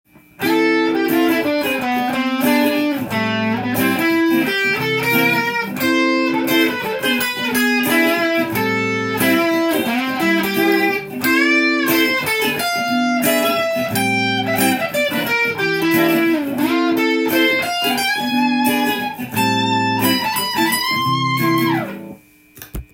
FM7/E7/Am7/C7
オリジナルギターソロtab譜
譜面通り弾いてみました